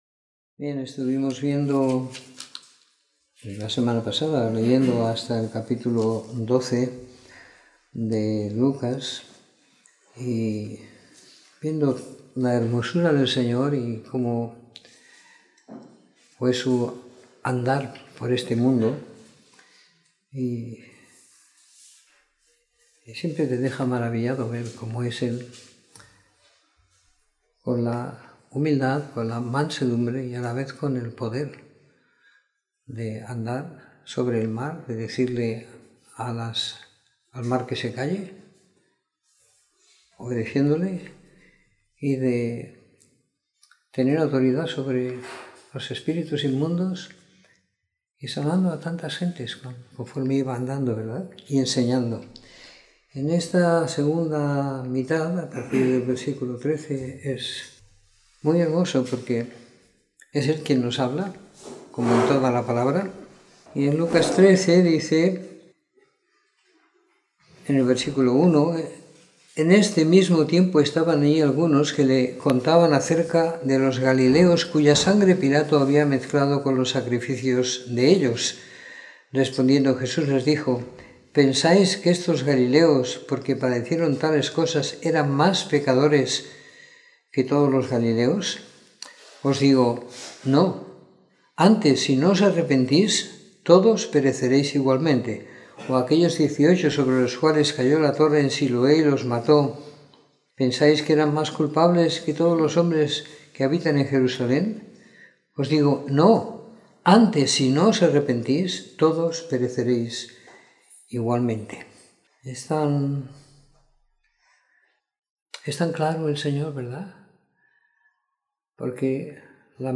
Comentario en Lucas 13-24 - 15 de Marzo de 2019